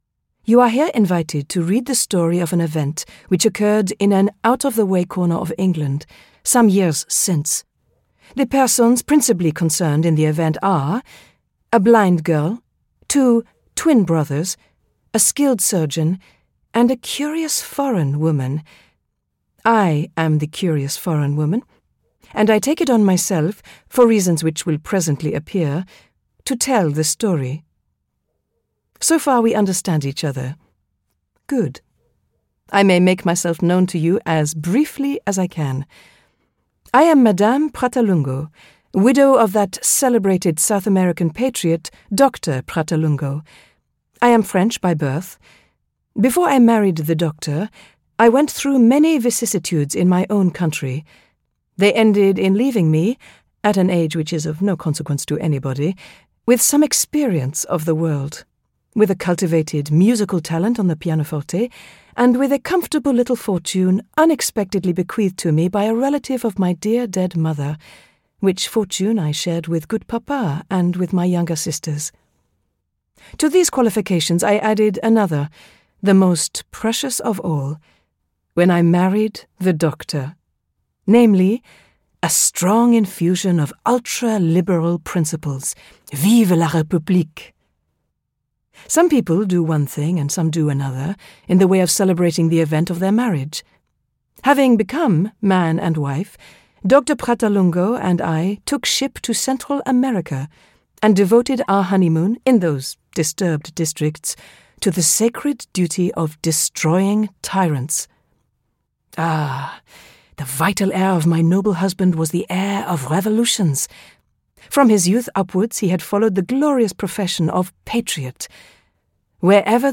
Poor Miss Finch audiokniha
Ukázka z knihy